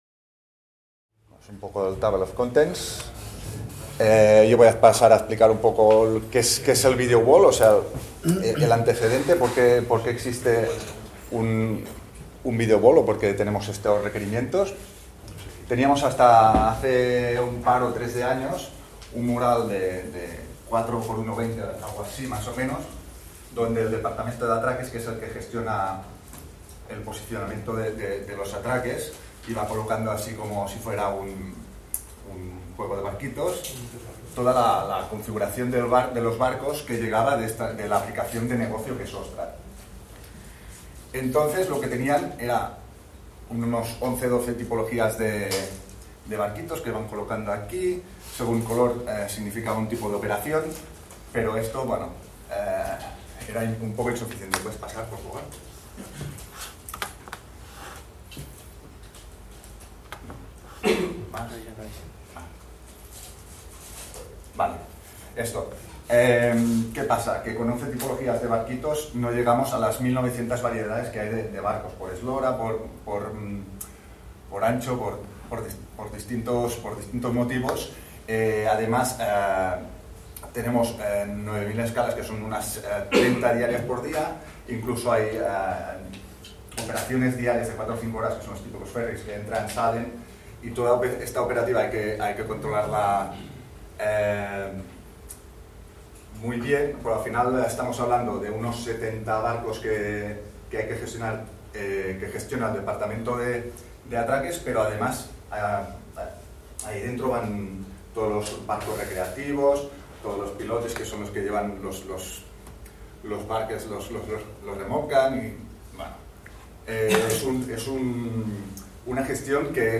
en el marc de les 18enes Jornades de SIG Lliure 2025 organitzades pel SIGTE de la Universitat de Girona. El Departament d'Operacions Marítimes del Port de Barcelona ha implementat un sistema de videowall intel·ligent per a la monitorització en temps real de l'activitat marítima. La solució integra informació AIS, capes GIS de la infraestructura portuària, dades meteorològiques, nivell del mar i direcció del vent.